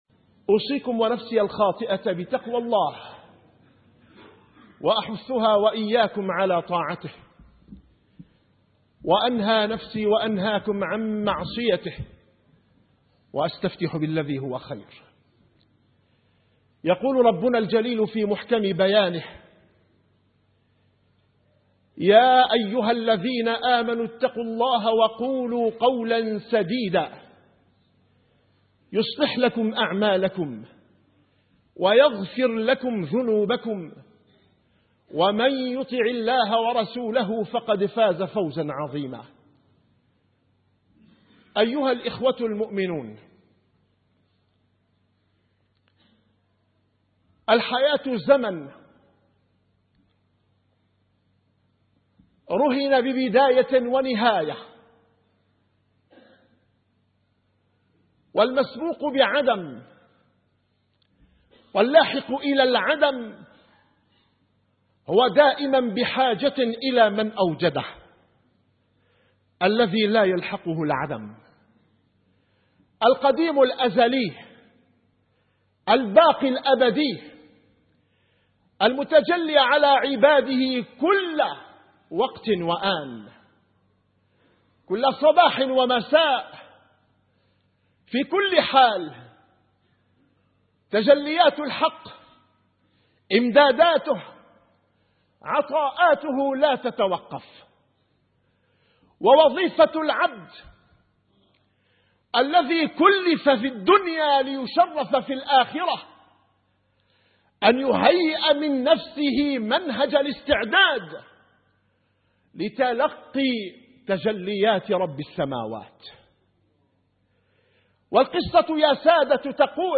- الخطب